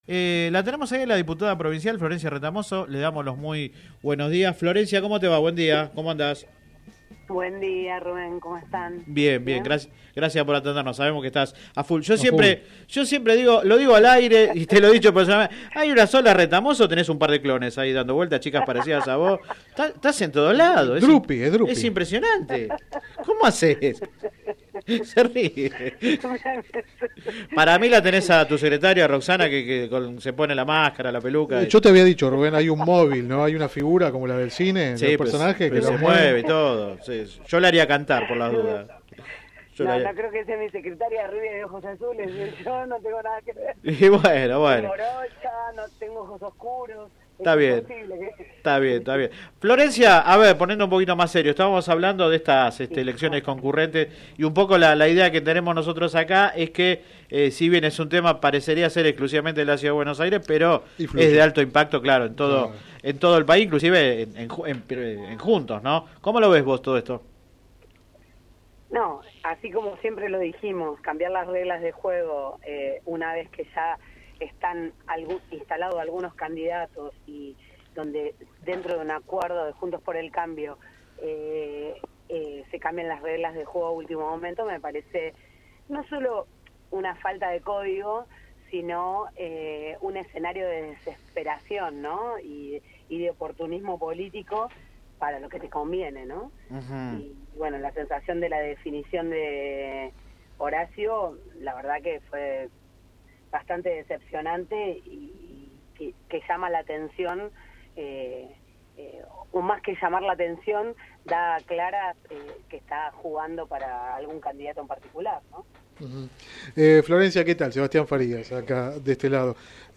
La legisladora hizo el anuncio en el programa radial Sin Retorno (lunes a viernes de 10 a 13 por GPS El Camino FM 90 .7 y AM 1260).
entrevista radial